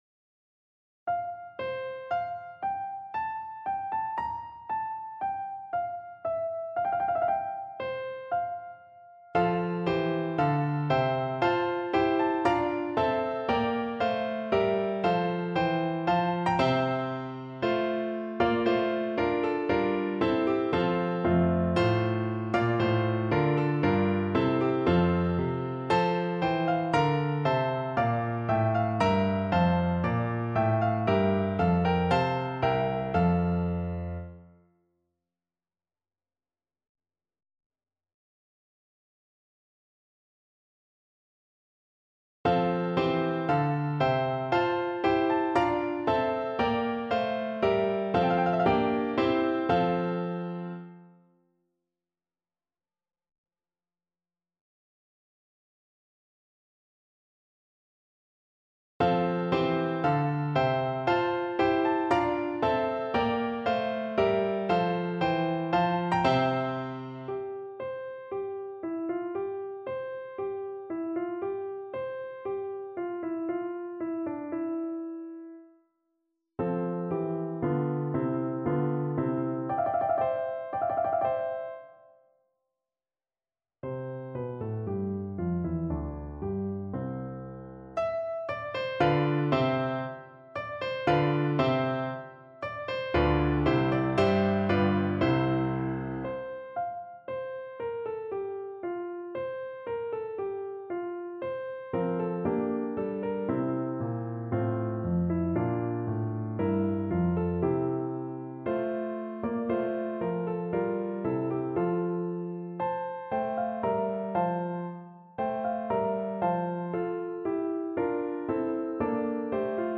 4/4 (View more 4/4 Music)
Classical (View more Classical Contralto Voice Music)